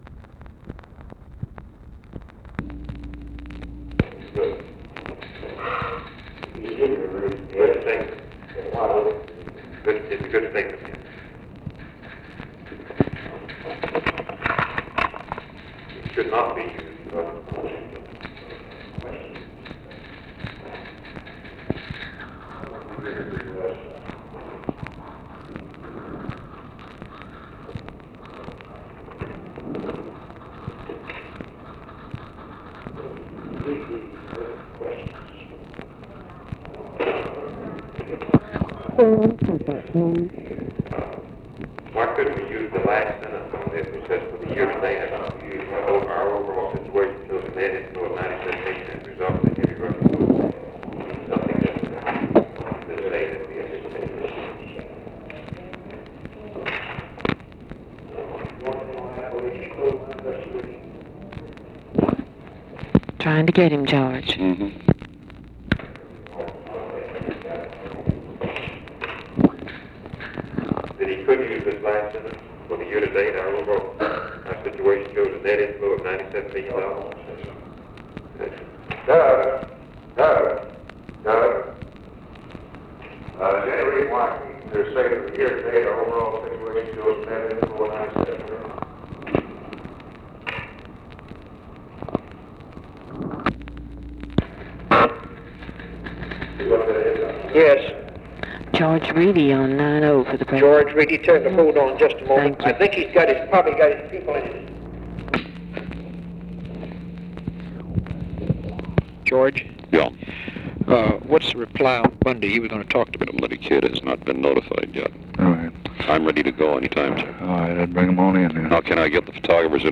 Conversation with GEORGE REEDY, OFFICE CONVERSATION and JACK VALENTI, June 2, 1964
Secret White House Tapes